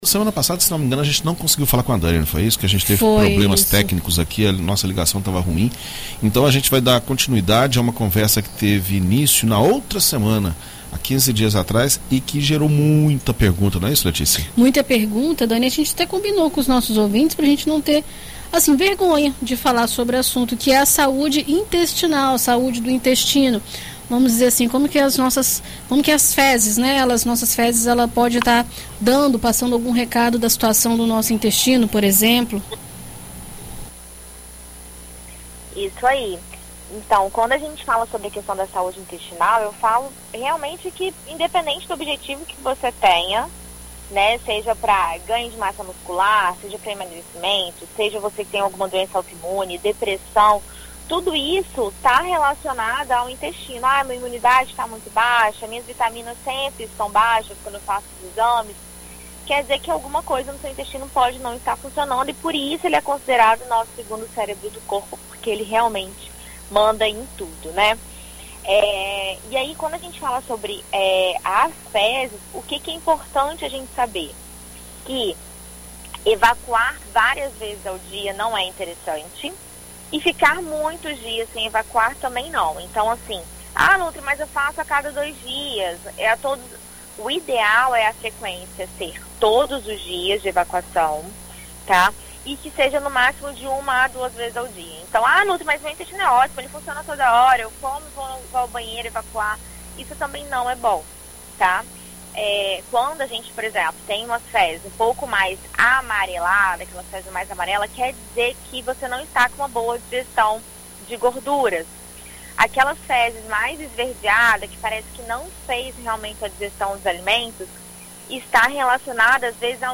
Na coluna desta semana na BandNews FM Espírito Santo